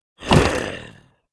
behemoth_attack1d.wav